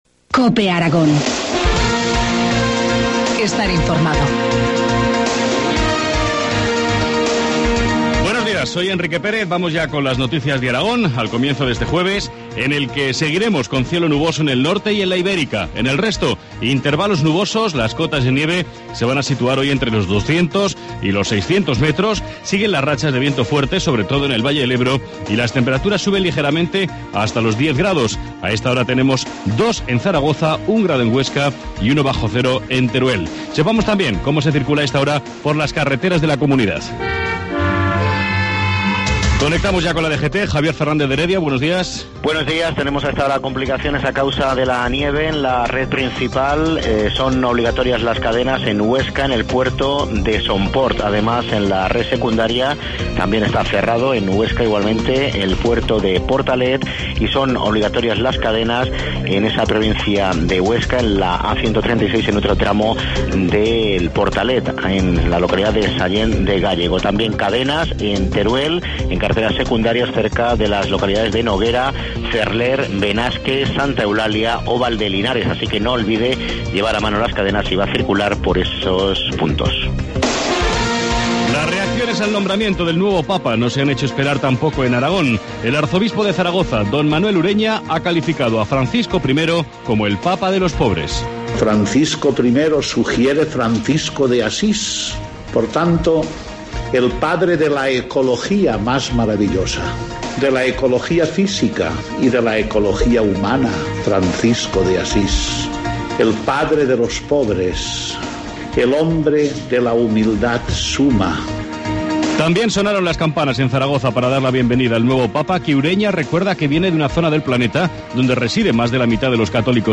Informativo matinal, jueves 14 de marzo, 7.25 horas